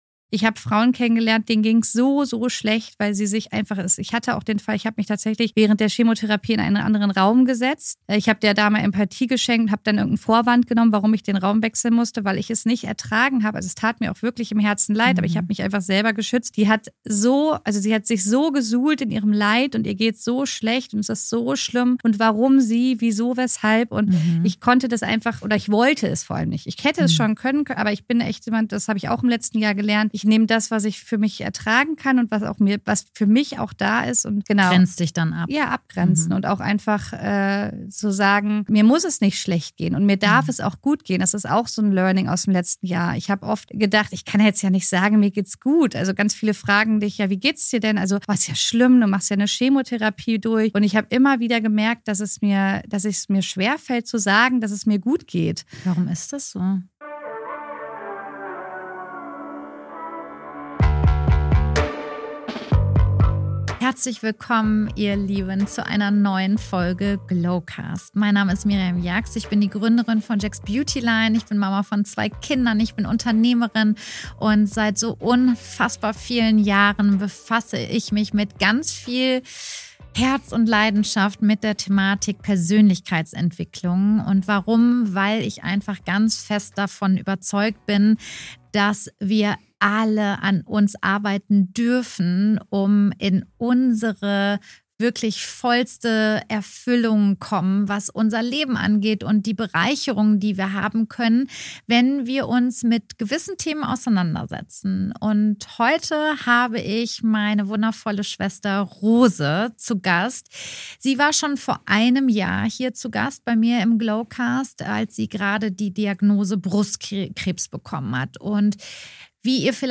Ein ehrliches, warmes Gespräch über Heilung, Familie und Vertrauen in das Leben. Am Ende bleibt eine Erkenntnis: Manchmal zeigt uns das Leben, wer wir wirklich sind.